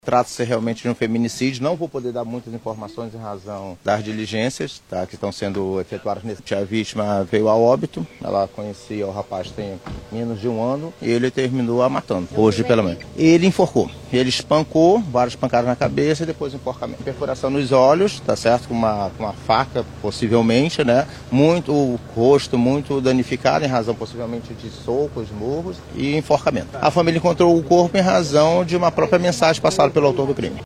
SONORA02_DELEGADO-1-1.mp3